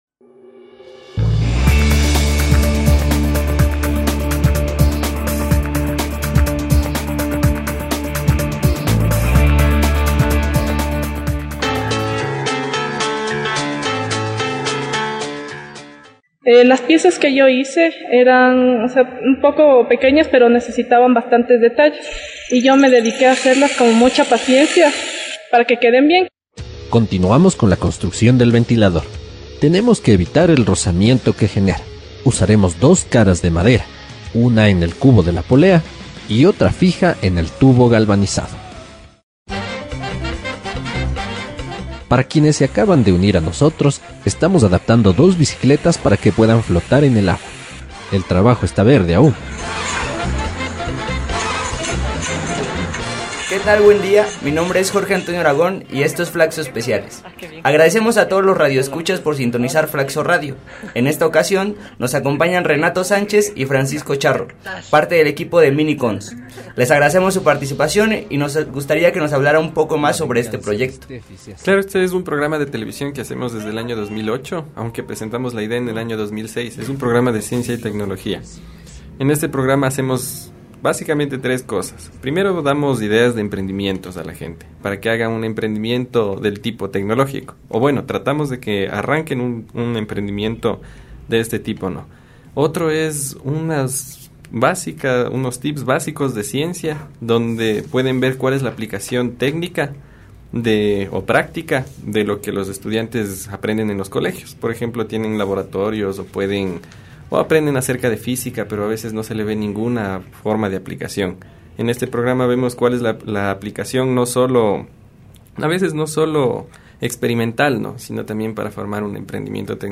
integrantes del equipo de MiniCons, para conversar sobre el proyecto, sus antecedentes y sus principales objetivos.